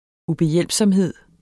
Udtale [ ubeˈjεlˀbsʌmˌheðˀ ]